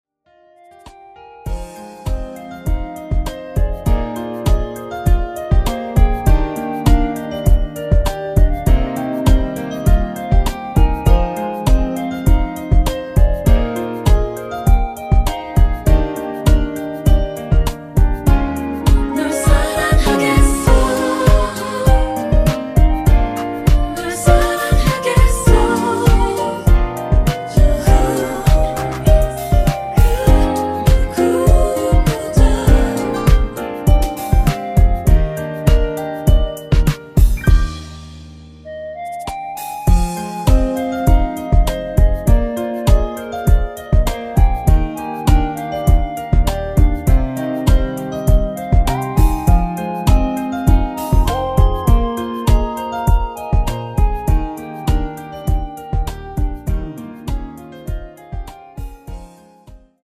Eb
노래방에서 노래를 부르실때 노래 부분에 가이드 멜로디가 따라 나와서
앞부분30초, 뒷부분30초씩 편집해서 올려 드리고 있습니다.